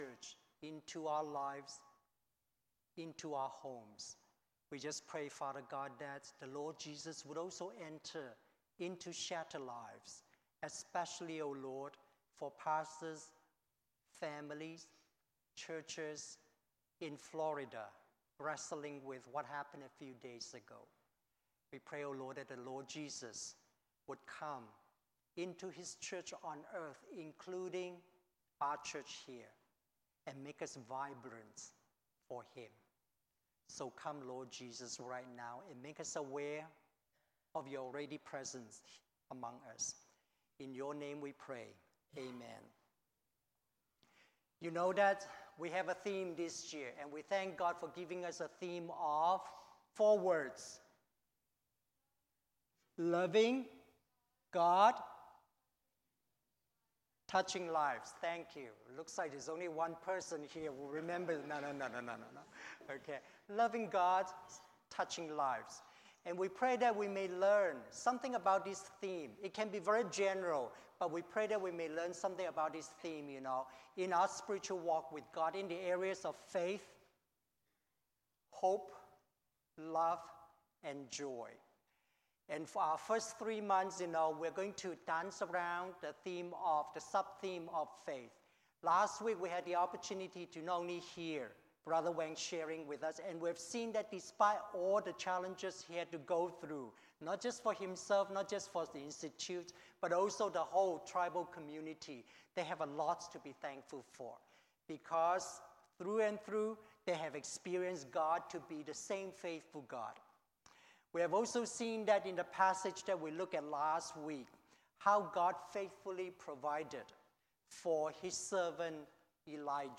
Sermon Notes TITLE: God Does Provide: With Refreshing Restoration TEXT: Exodus 15: 22-27 22 Then Moses led Israel from the Red Sea and they went into the Desert of Shur.